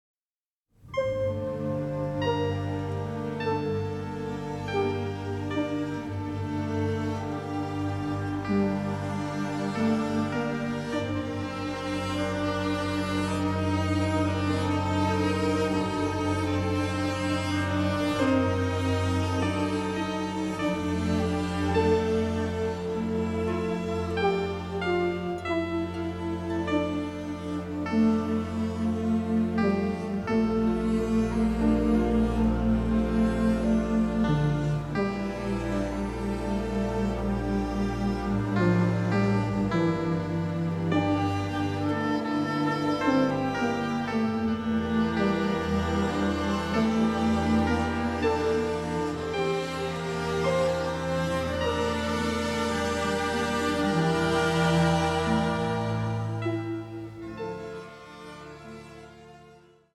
deeply delicate score